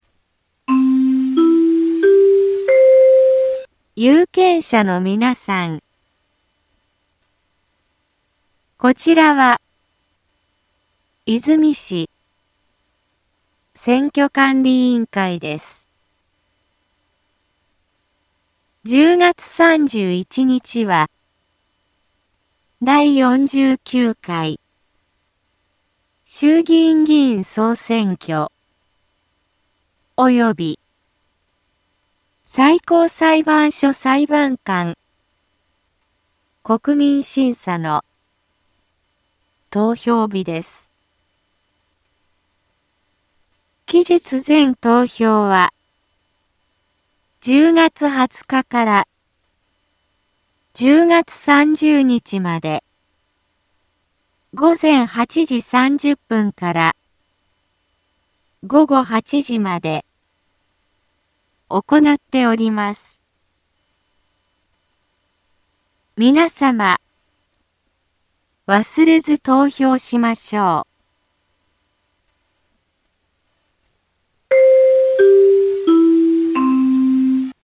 BO-SAI navi Back Home 災害情報 音声放送 再生 災害情報 カテゴリ：通常放送 住所：大阪府和泉市府中町２丁目７−５ インフォメーション：有権者のみなさん こちらは和泉市選挙管理委員会です １０月３１日は、第４９回衆議院議員総選挙及び最高裁判所裁判官国民審査の投票日です 期日前投票は、１０月２０日から１０月３０日まで午前８時３０分から午後８時まで行なっております 皆様、忘れず投票しましょう